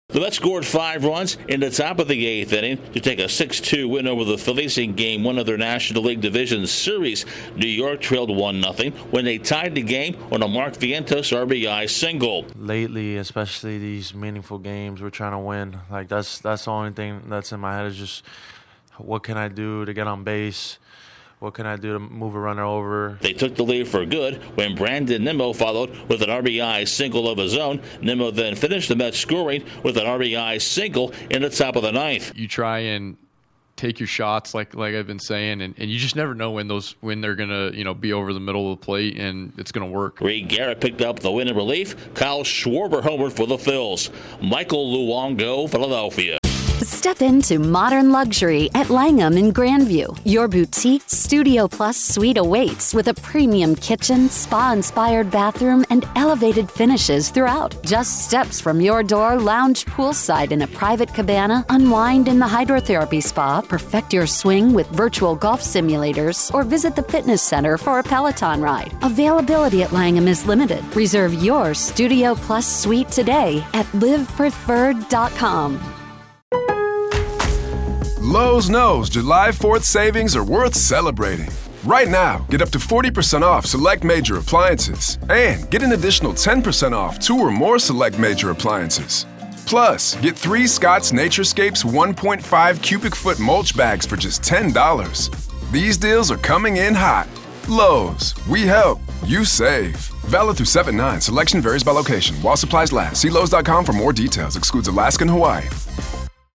The Mets continue their recent success by rallying past the Phillies. Correspondent